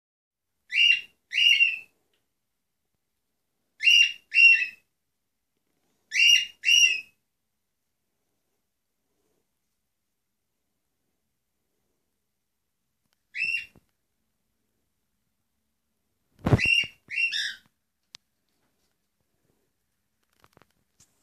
Unsere Nymphensittiche
Zu unserer Überraschung kann Fritzi schön singen (Fritzi1.mp3,